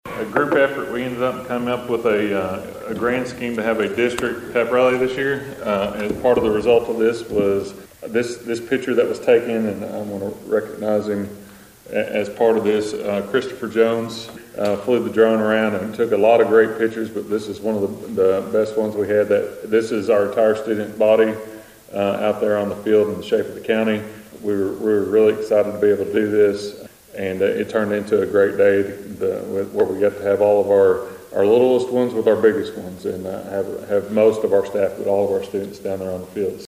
The Caldwell County Board of Education met in regular session Monday night in the Butler Auditorium.